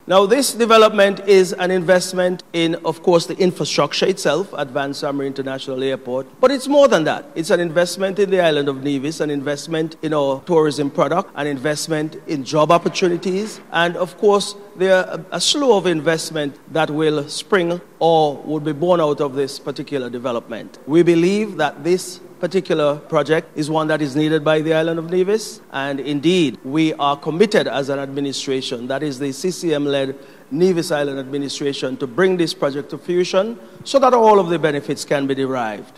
A consultation took place at the Pond Hill Community Centre on Thursday, November 14th, to update the Nevisian public about the Vance W. Amory International Airport development project.
Special Advisor to the Premier Hon. Alexis Jeffers, said the project would positively impact all sectors such as Tourism and bolster job opportunities: